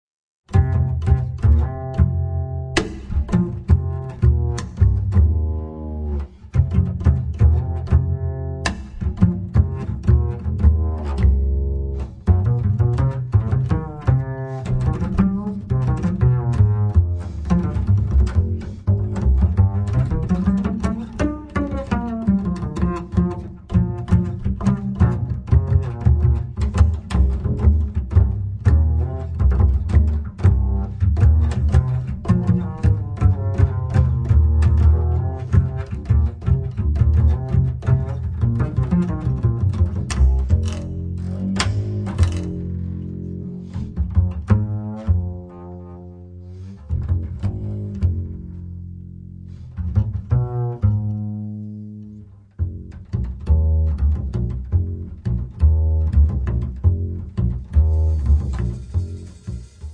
batteria
contrabbasso
chitarre
vibrafono
pianoforte
sax soprano